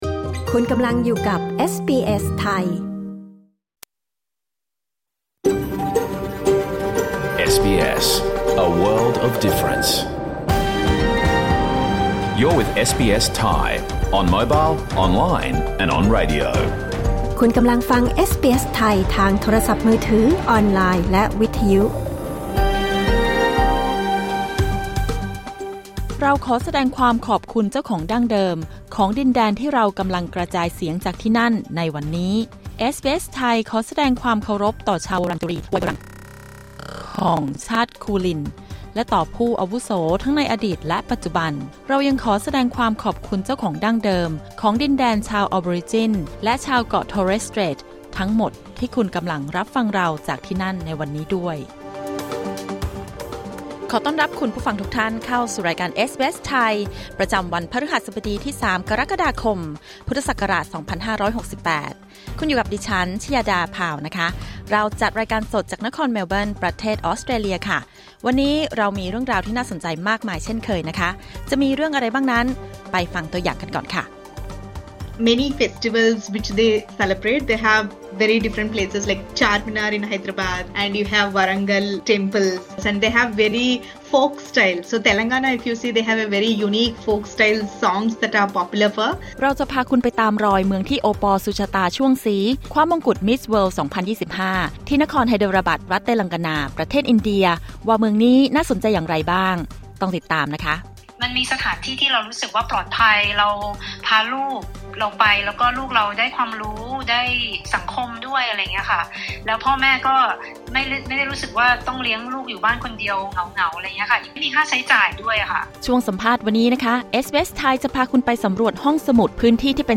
รายการสด 3 กรกฎาคม 2568